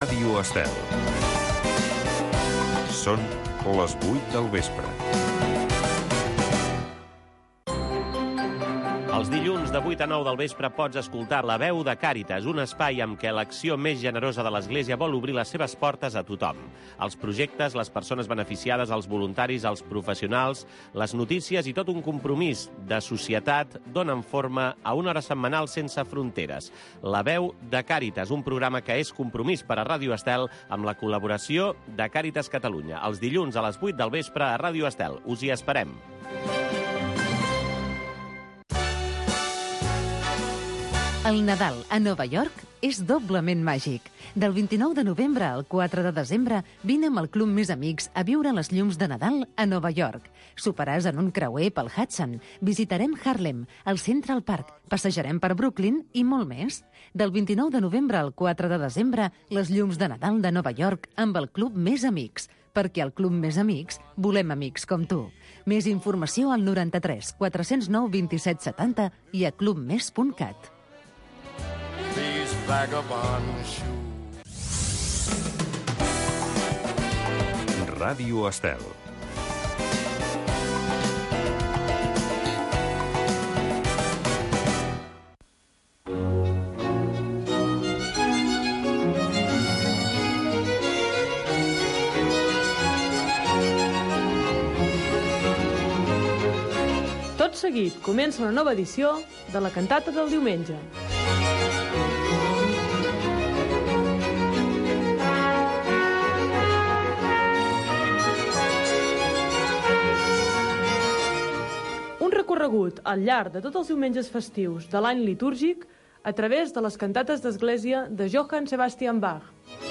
Audició d’una Cantata de Johann Sebastian Bach, destinada al Diumenge corresponent del calendari luterà